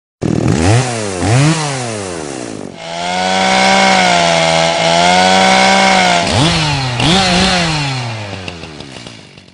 Chainsaw Sound